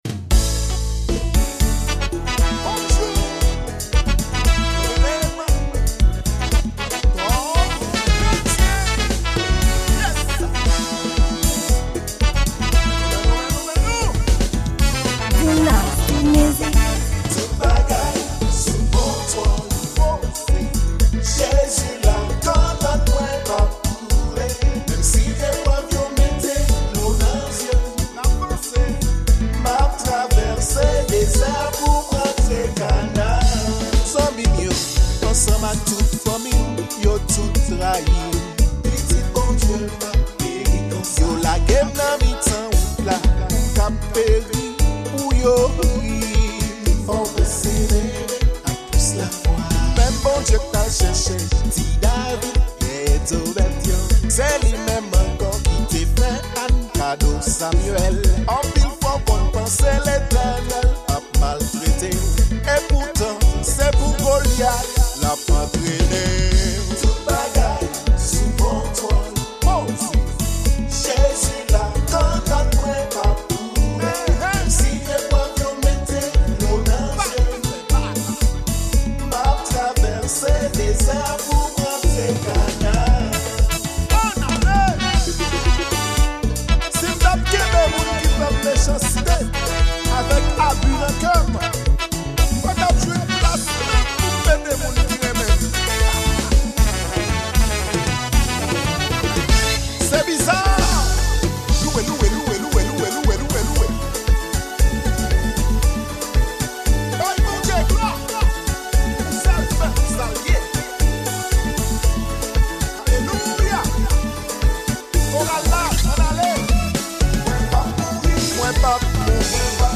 Genre: Gospel